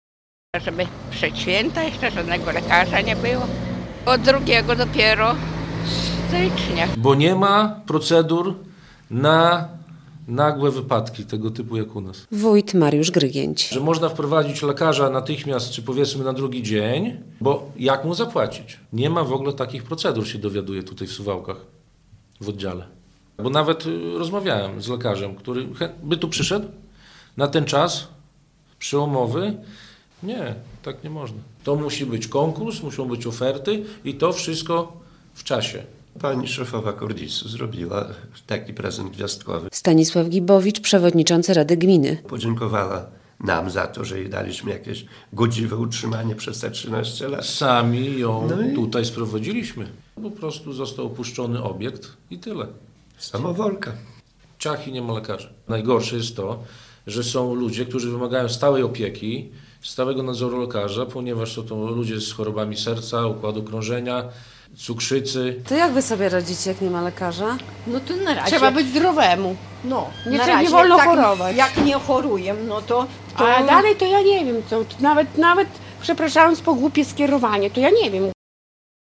Zamknięty ośrodek zdrowia w Szypliszkach - relacja